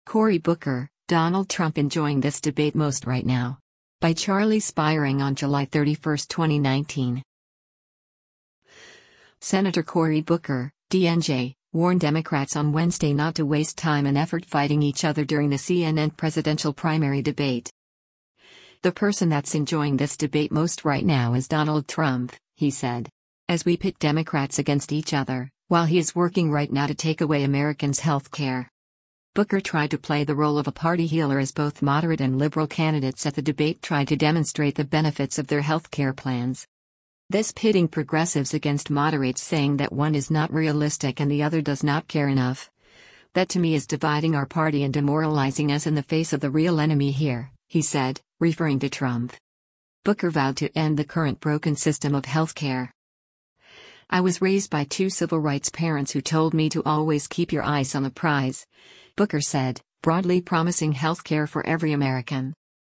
Sen. Cory Booker (D-NJ) warned Democrats on Wednesday not to waste time and effort fighting each other during the CNN presidential primary debate.